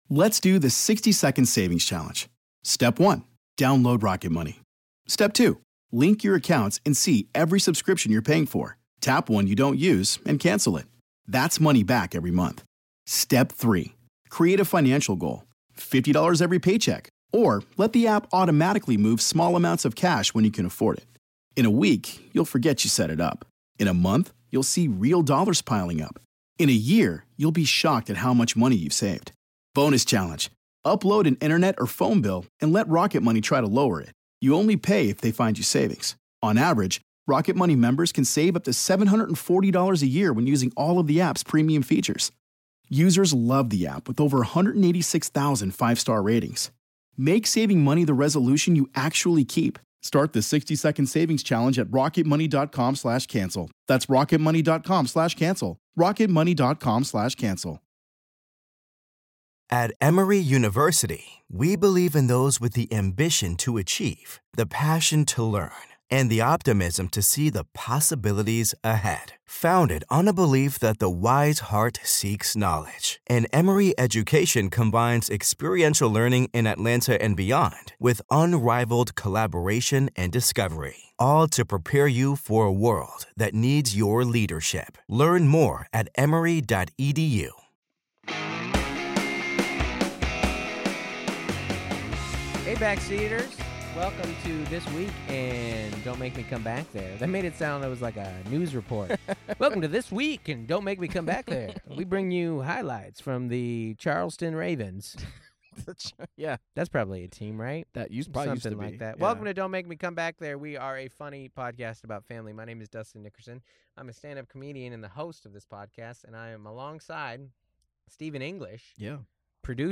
chat today from the comedy condo. They discuss talk West Coast vs. Southern comedy, parents and parenting, being single vs. being married and, for some reason, the movie Serendipity.